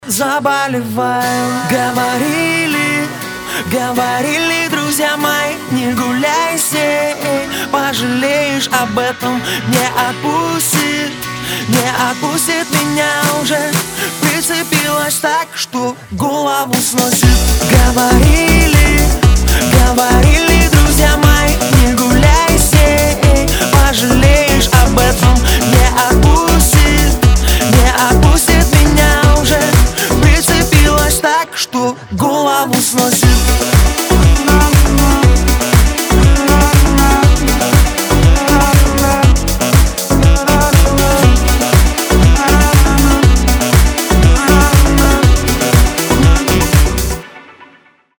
• Качество: 320, Stereo
поп
гитара
мужской вокал
красивый мужской голос
веселые
dance
Саксофон
Electropop